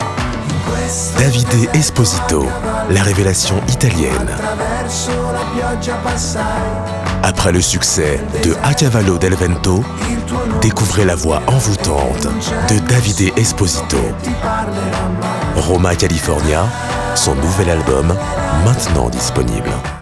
Voix-off grave jeune adulte / adulte
Kein Dialekt
Sprechprobe: Werbung (Muttersprache):